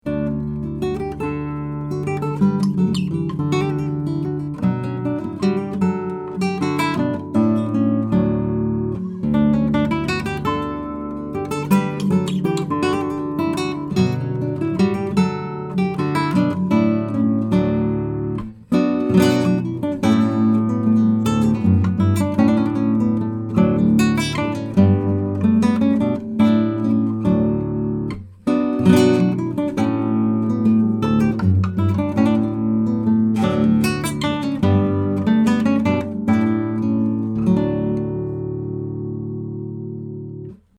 Here are 39 quick, 1-take MP3s of these mics into a Presonus ADL 600 preamp with a Rosetta 200 A/D converter. This is straight signal with no additional EQ or effects:
10-STRING CLASSICAL HARP GUITAR: